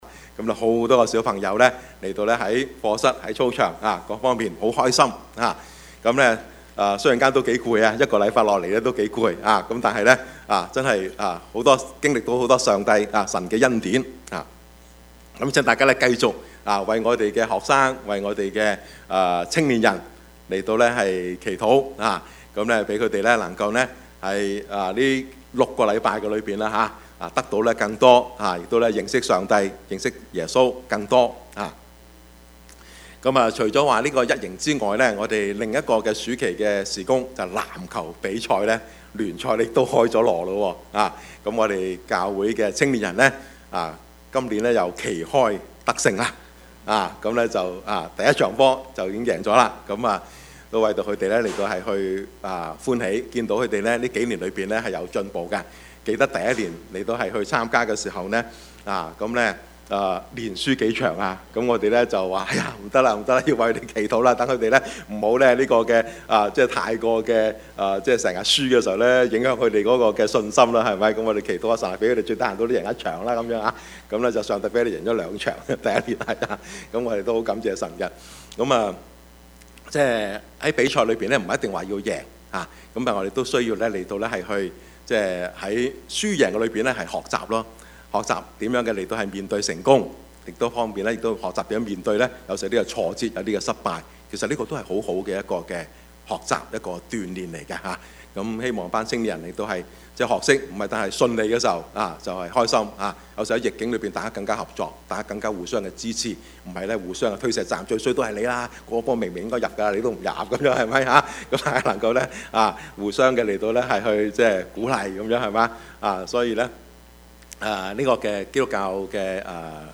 Service Type: 主日崇拜
Topics: 主日證道 « 聖靈的合一 健康成長的教會 »